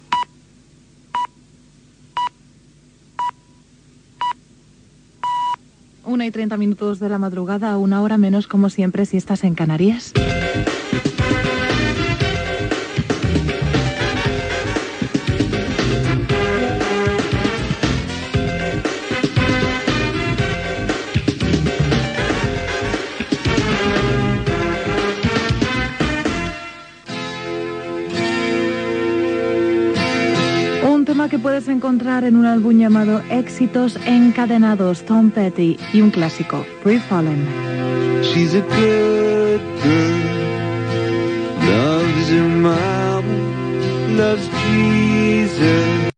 bd3987da8c08ccb2780d2dbf224be0674ff9a63f.mp3 Títol Europa FM Emissora Europa FM Barcelona Cadena Europa FM Titularitat Privada estatal Descripció Senyals horaris i presentació d'una cançó. Gènere radiofònic Musical Data emissió 2011-01 Banda FM Localitat Barcelona Comarca Barcelonès Durada enregistrament 00:46 Idioma Castellà Notes Fragment extret del blog "Radiochips".